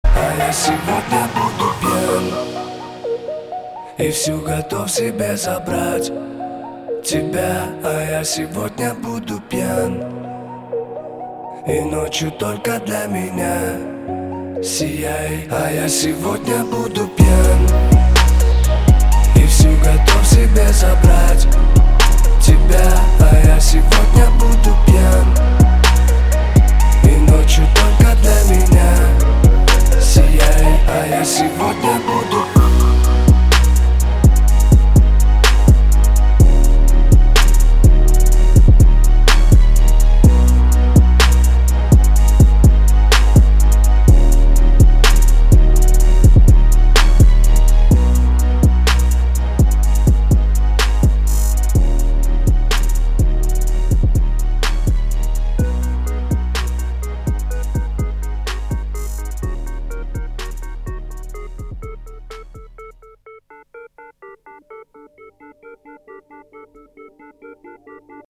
• Качество: 320, Stereo
мужской вокал
спокойные
Rap